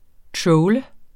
Udtale [ ˈtɹɔwlə ]